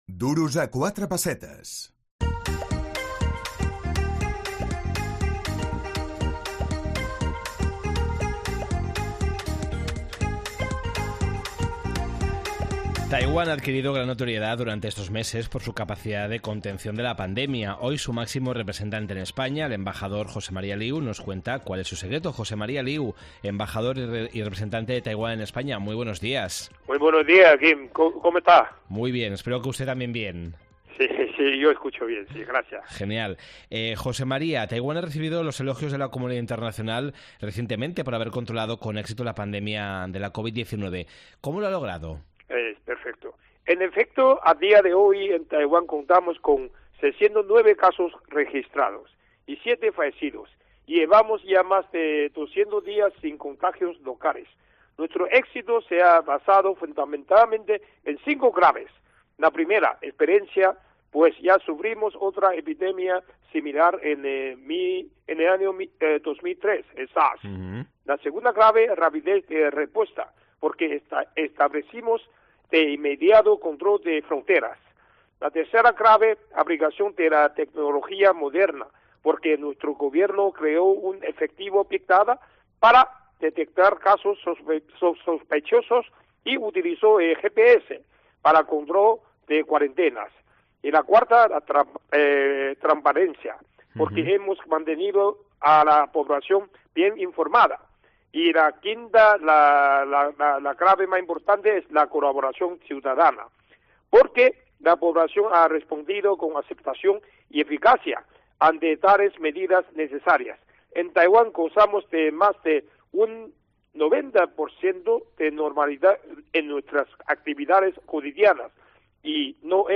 Jose Maria Liu, ambaixador i representant de Taiwan en España
Duros a quatre pessetes, el programa d’economia de COPE Catalunya i Andorra.